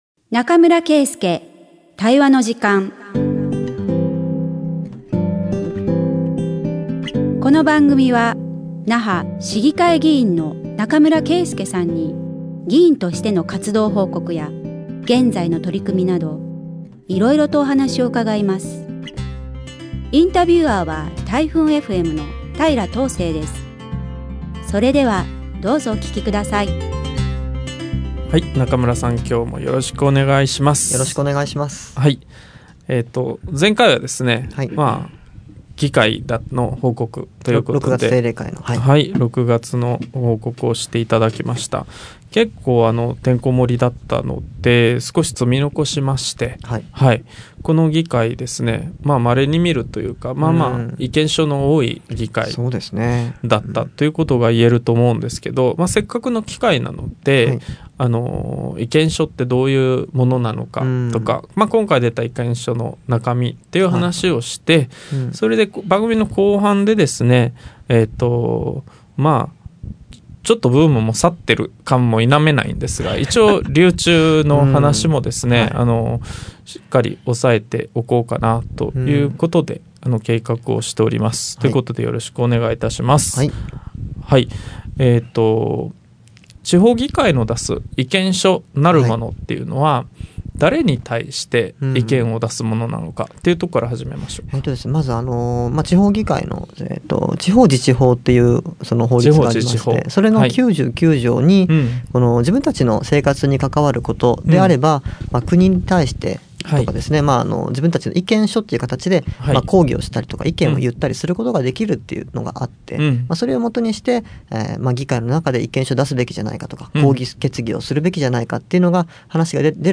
那覇市議会議員中村圭介が議員活動や現在の取組みを語る20分